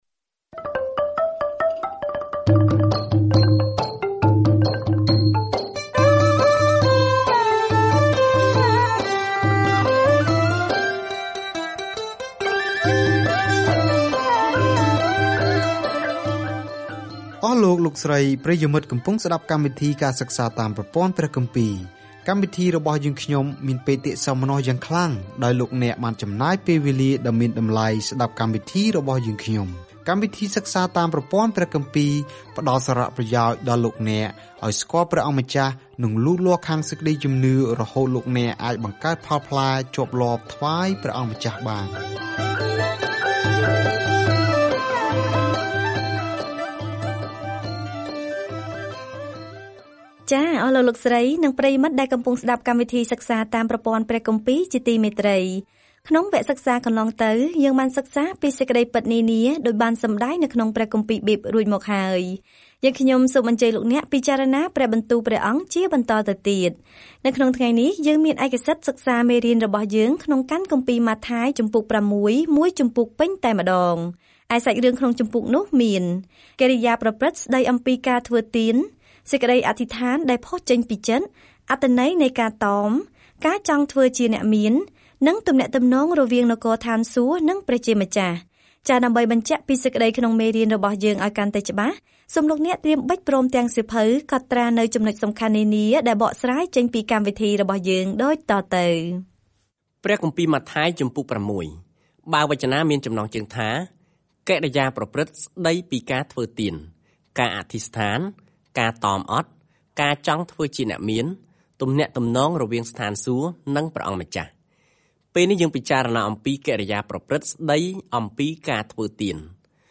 ម៉ាថាយបង្ហាញដល់អ្នកអានសាសន៍យូដានូវដំណឹងល្អថា ព្រះយេស៊ូវគឺជាព្រះមេស្ស៊ីរបស់ពួកគេ ដោយបង្ហាញពីរបៀបដែលជីវិត និងកិច្ចបម្រើរបស់ទ្រង់បានបំពេញតាមទំនាយក្នុងព្រះគម្ពីរសញ្ញាចាស់ ។ ការធ្វើដំណើរប្រចាំថ្ងៃតាមម៉ាថាយ ពេលអ្នកស្តាប់ការសិក្សាជាសំឡេង ហើយអានខគម្ពីរដែលជ្រើសរើសពីព្រះបន្ទូលរបស់ព្រះ។